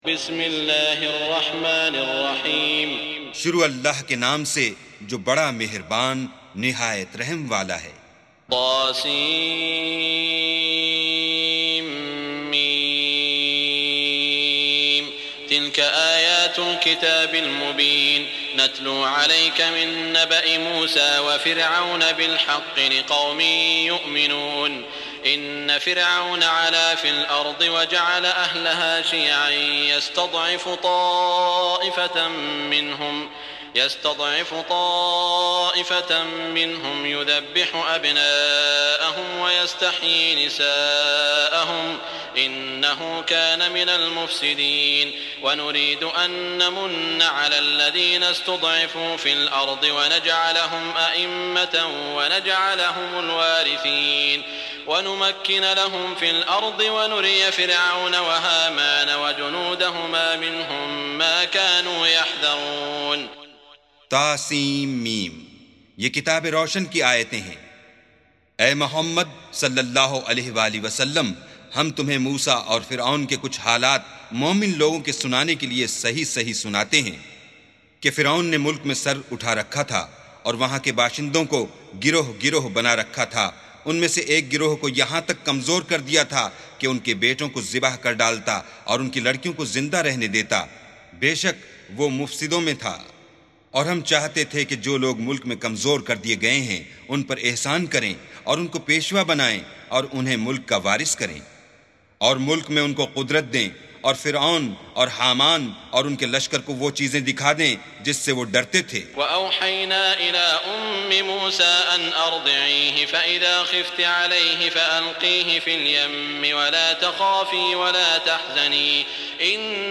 سُورَةُ القَصَصِ بصوت الشيخ السديس والشريم مترجم إلى الاردو